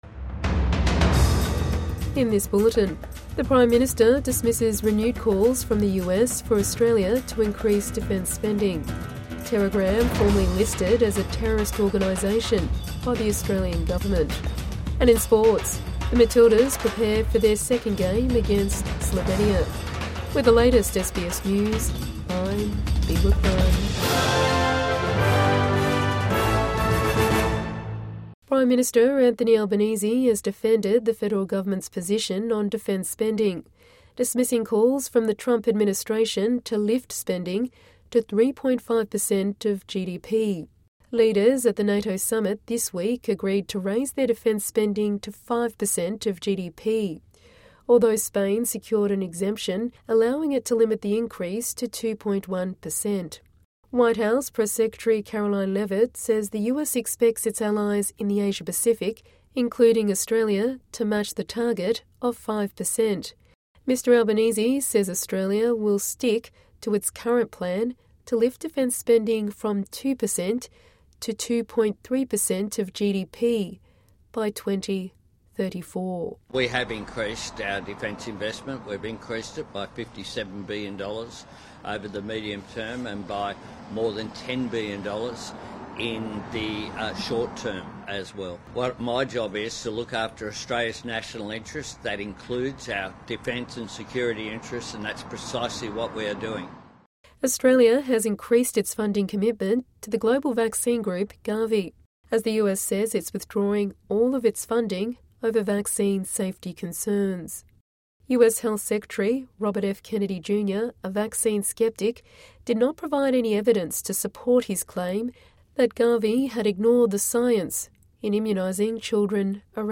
PM defends decision on defence spending goal | Midday News Bulletin 27 June 2025